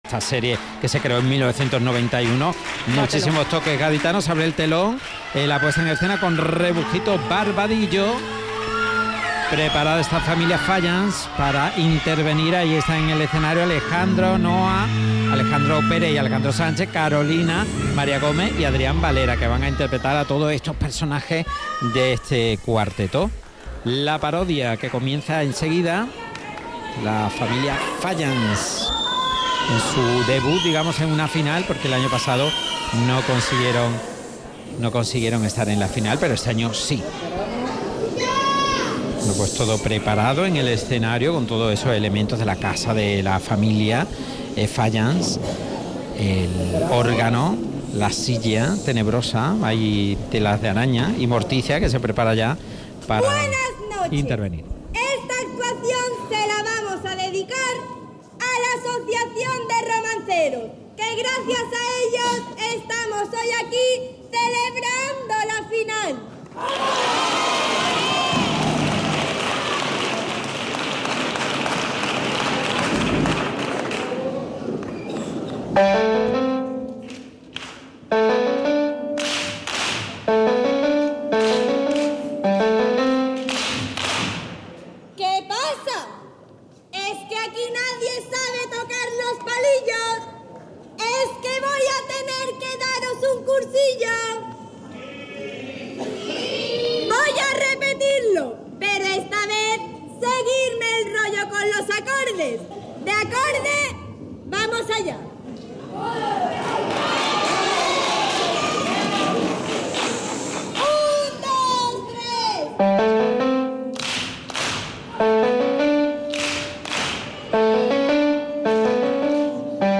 Audio de La cantera del Carnaval de Cádiz en Podium Podcast
Cuarteto Infantil - La familia fallans Final